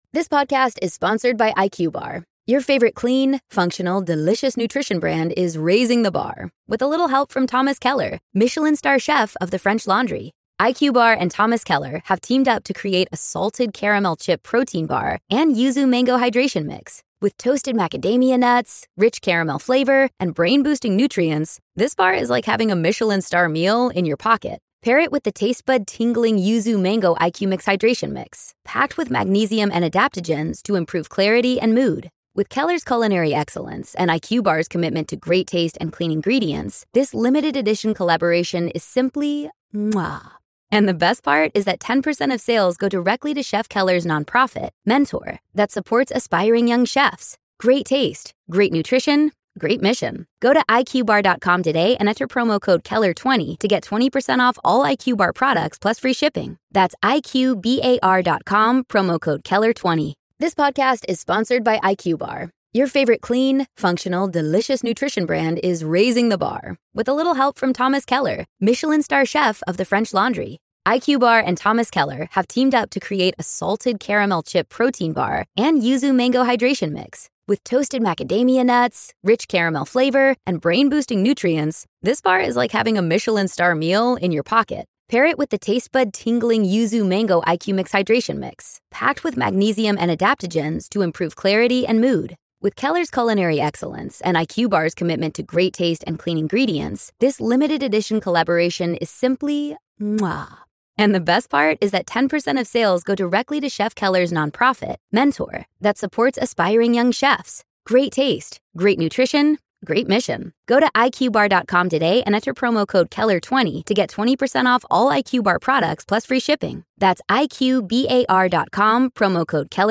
Could the Playboy Mansion be haunted? We sit down with Bridget Marquardt—Playmate, paranormal enthusiast, and one of Hugh Hefner’s former girlfriends—to uncover the ghost stories, eerie moments, and secrets that still linger in the mansion's walls. This is Part Two of our conversation.